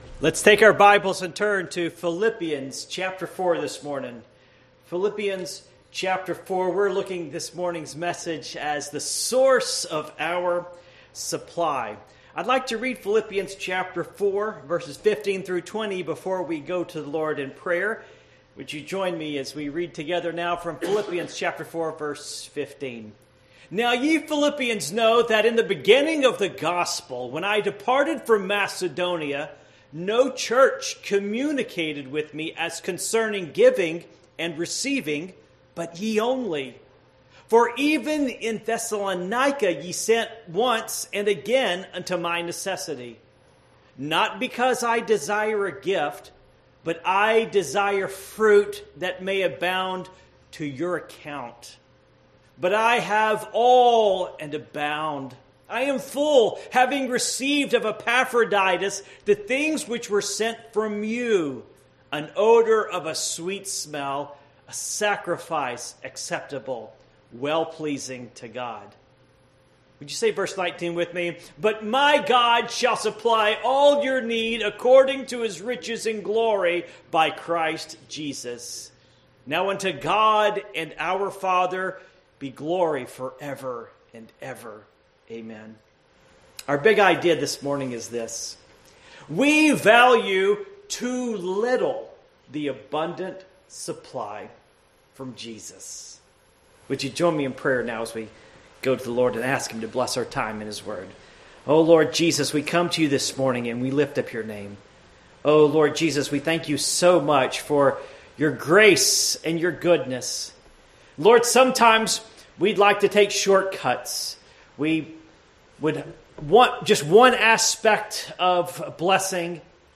Passage: Philippians 4:15-20 Service Type: Morning Worship